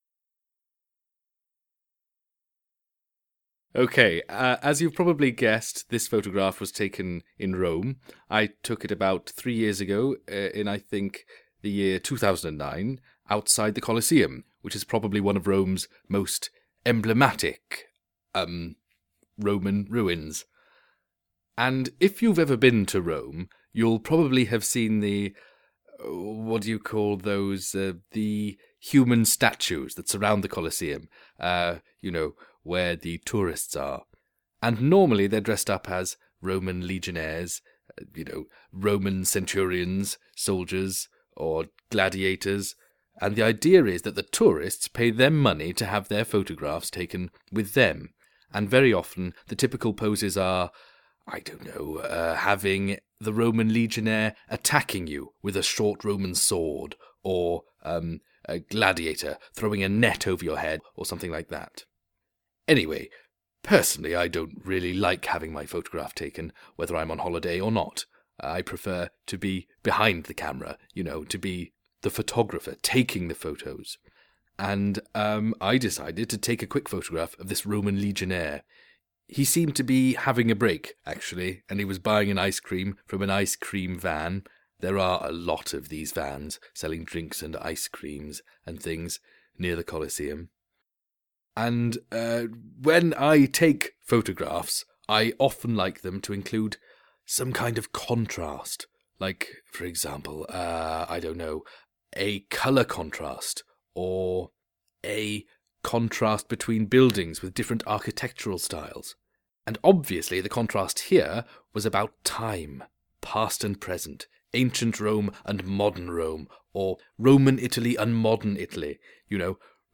Each pack in this series includes an audio recording of the photographer talking about their photo, together with a complete downloadable lesson plan on how to exploit the image and the audio.